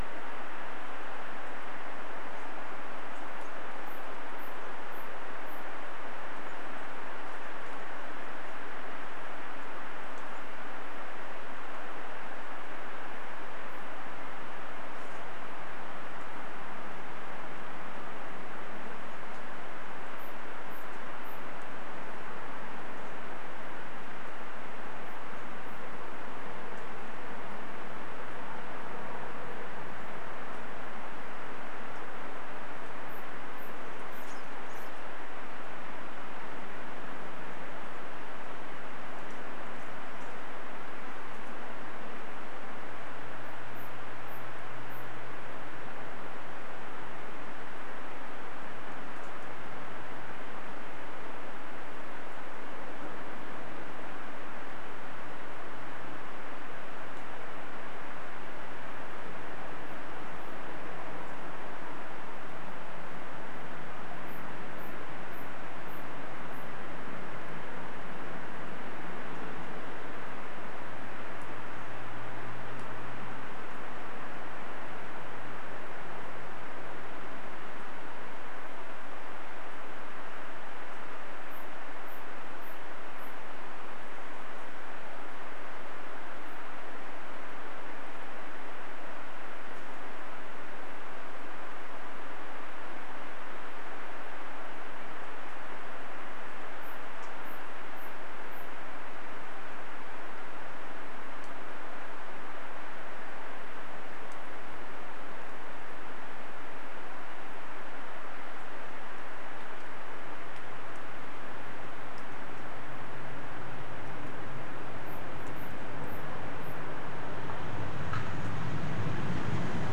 Demonstration soundscapes
anthropophony
biophony